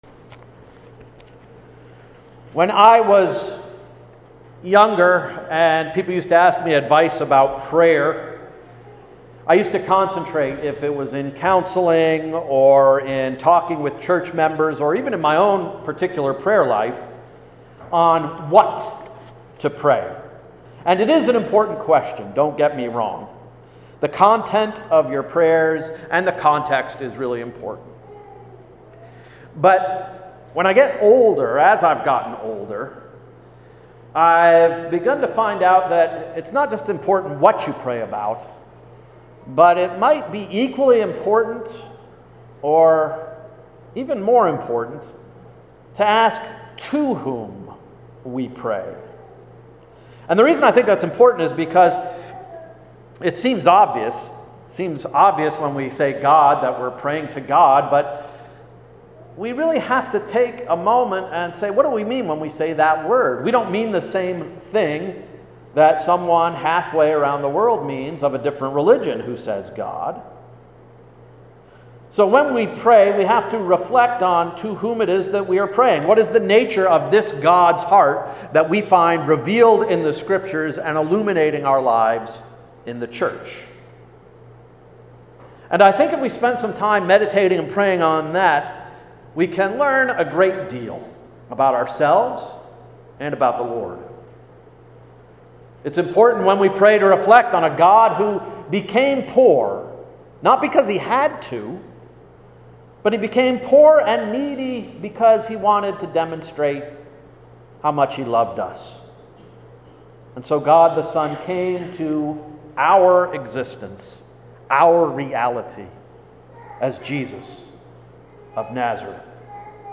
July 2, 2017 Sermon — “Testifying to Newness”